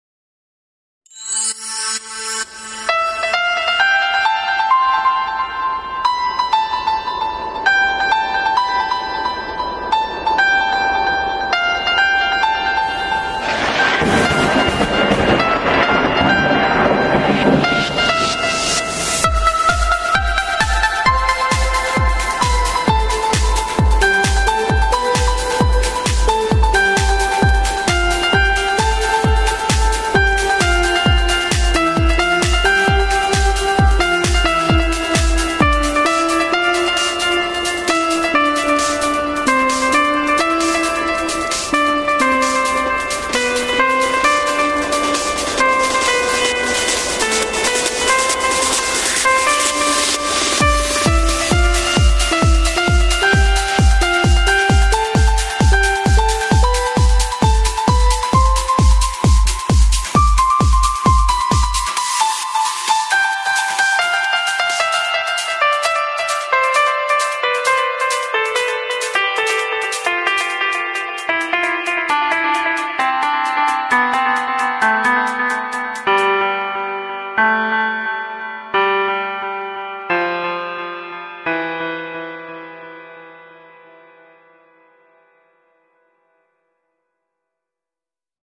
آهنگهای پاپ فارسی
بی کلام